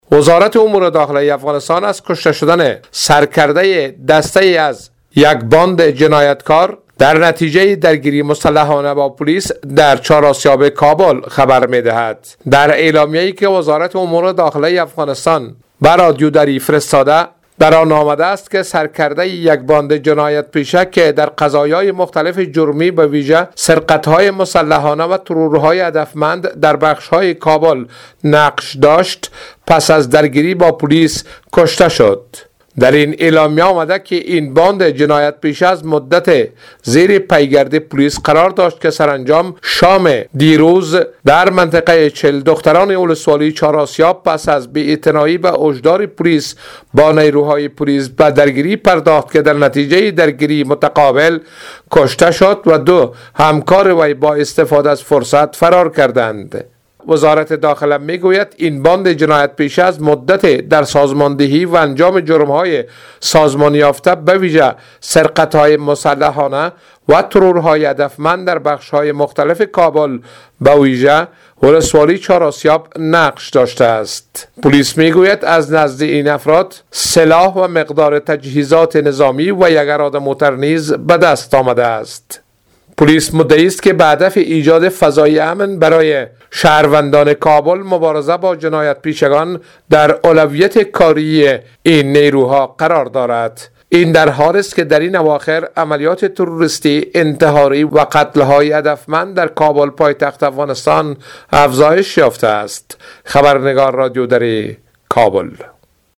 جزئیات در گزارش